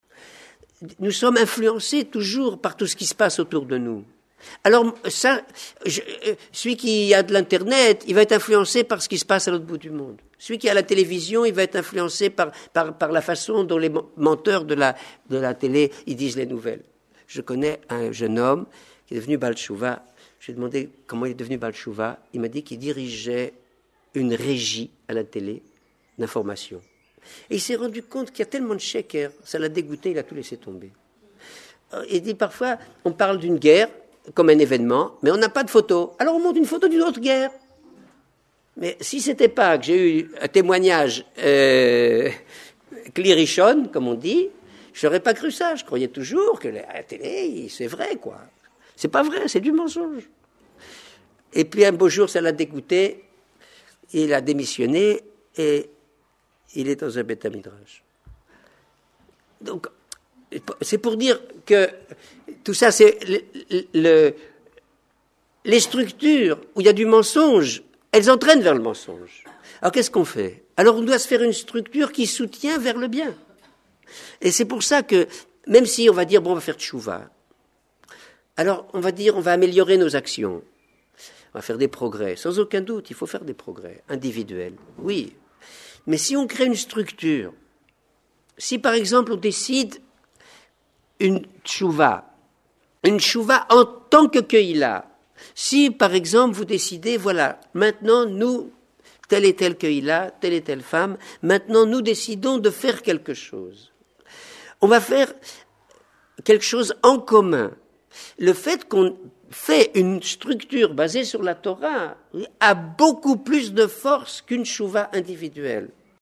C’était le 28 ou 29 Iyar 5764 – 19 mai 2004 devant un public féminin, probablement à Epinay, en région Parisienne.